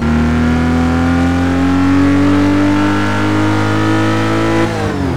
Index of /server/sound/vehicles/lwcars/lotus_esprit